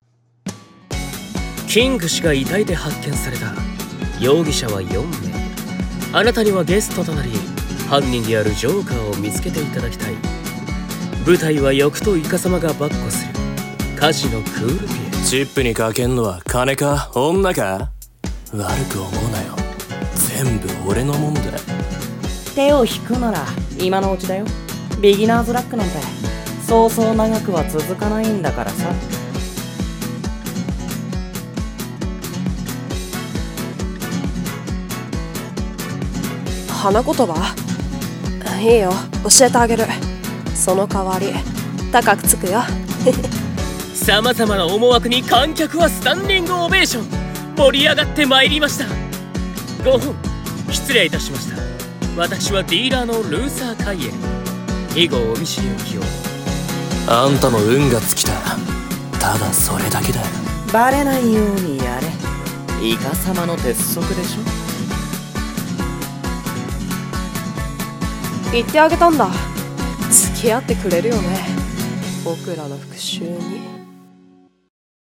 声劇【Who is the Joker？】コラボ用